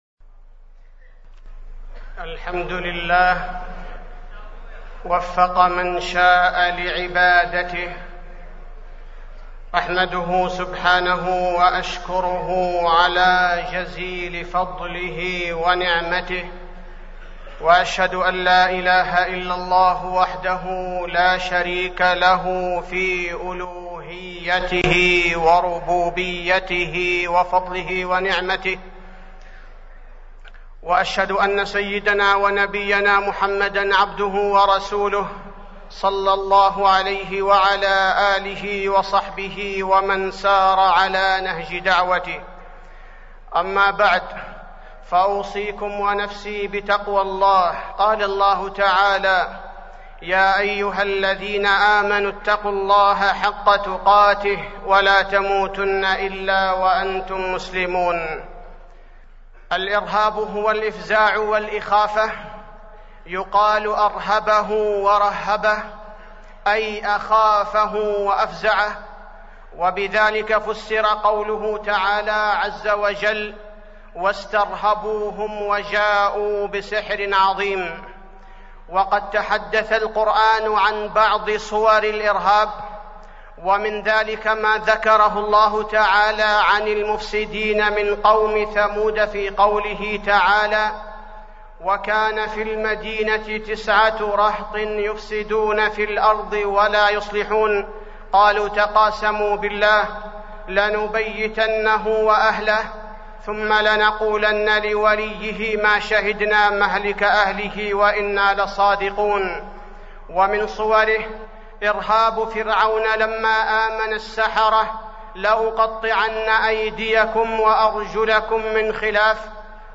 تاريخ النشر ٢٤ ذو الحجة ١٤٢٥ هـ المكان: المسجد النبوي الشيخ: فضيلة الشيخ عبدالباري الثبيتي فضيلة الشيخ عبدالباري الثبيتي حقيقة الإرهاب